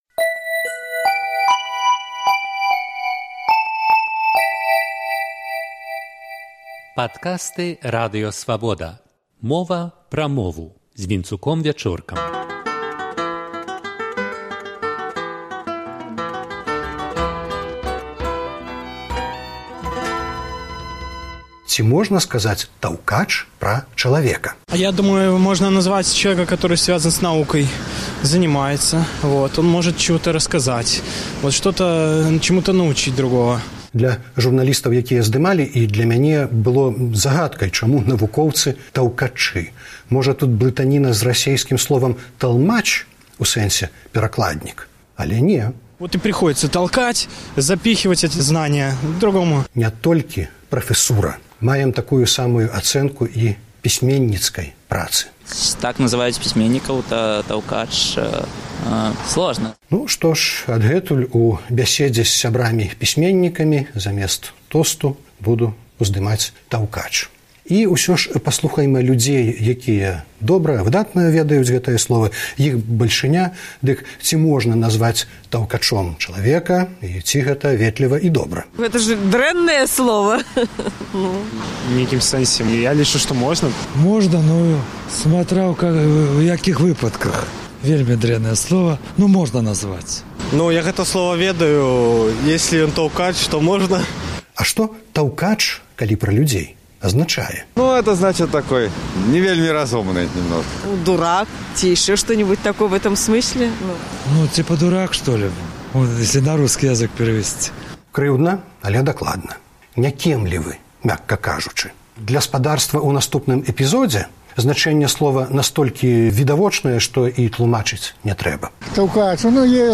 „Таўкач“ — гэта прафэсар? А мо пісьменьнік? Менчукі на вуліцы выказваюць вэрсіі.